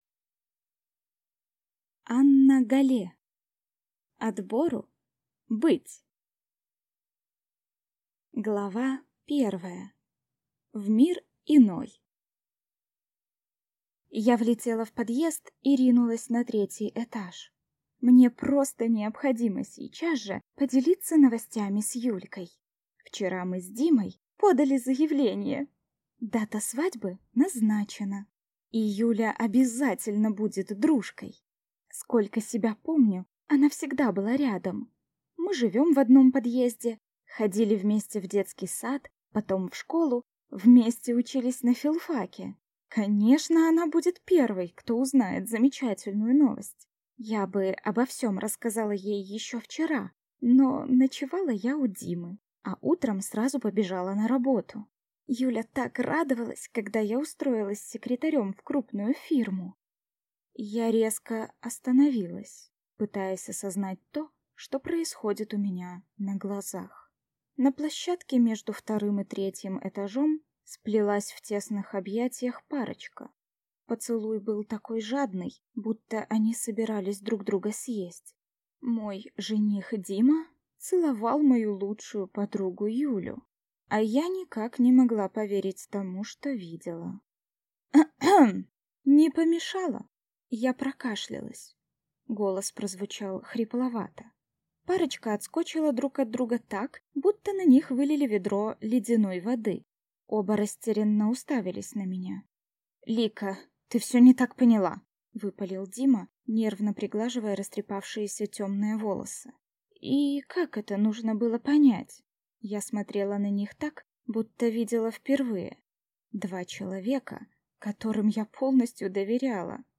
Аудиокнига Отбору – быть!